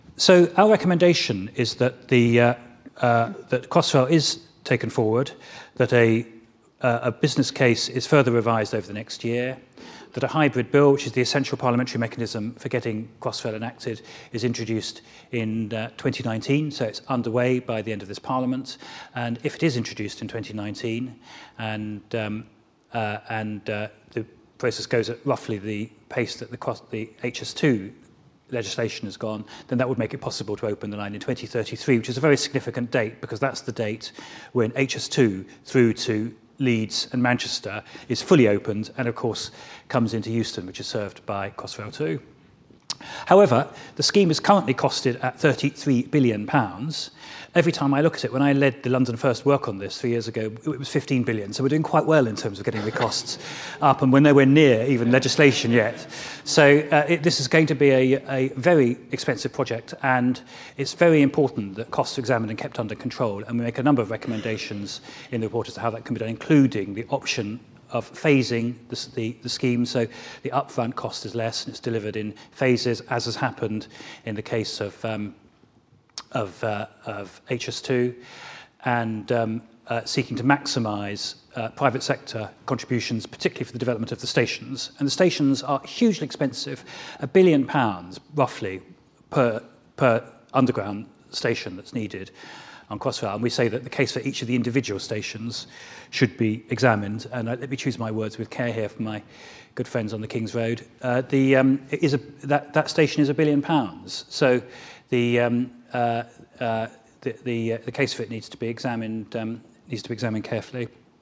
Lord Adonis talks about why a hybrid bill for Crossrail 2 should be introduced by 2019 at our London Infrastructure Summit.